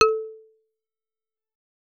Xyl_ASharp2.L.wav